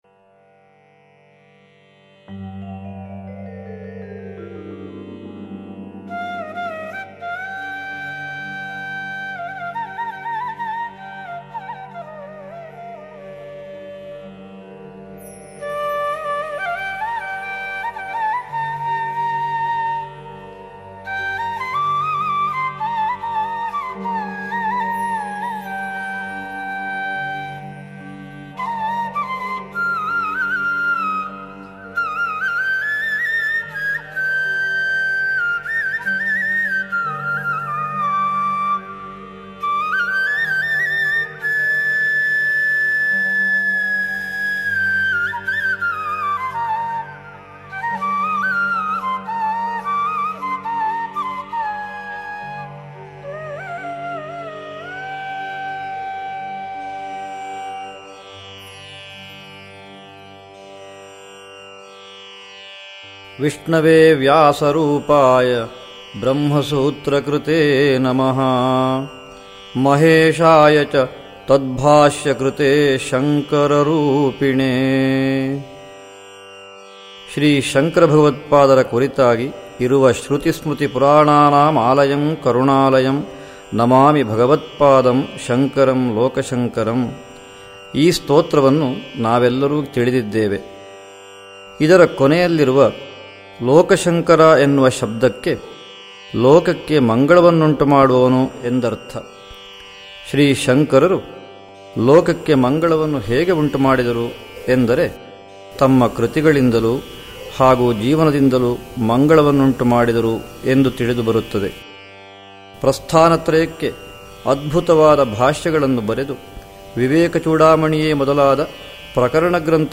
Anugrahavachana of Sri Sri Shankara Bharati Swamiji